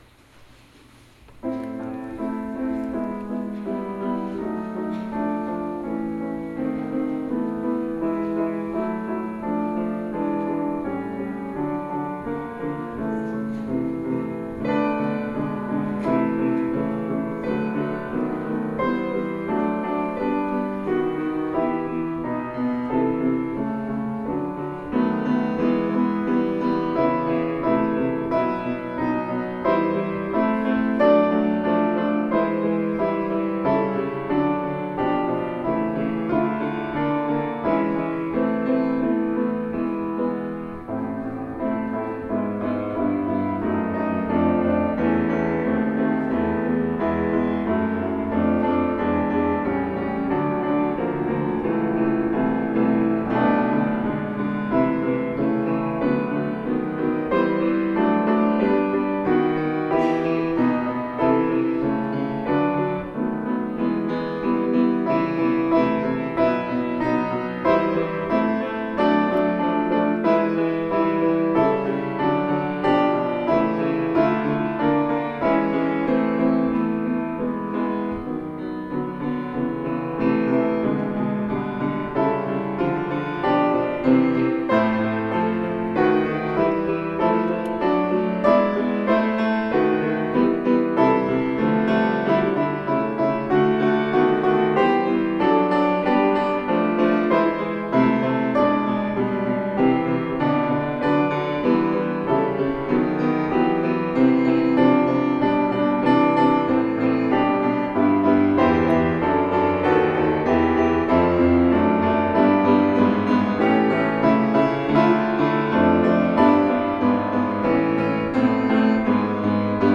[Fuusm-l] Some music from past services